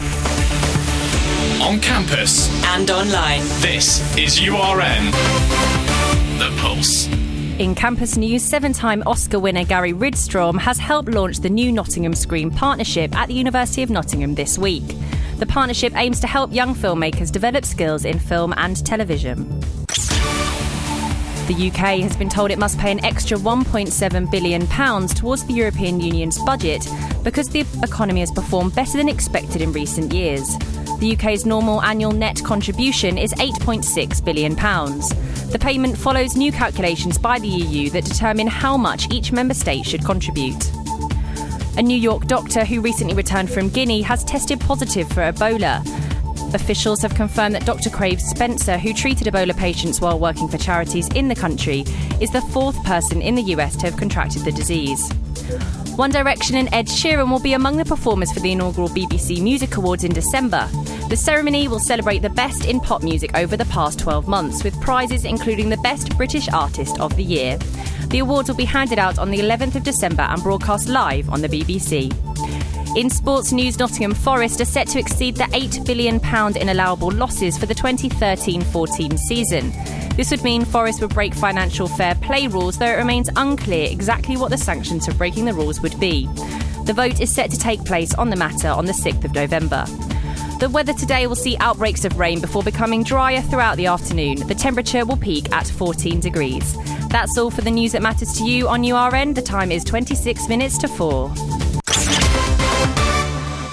All of the latest news that matters to you on URN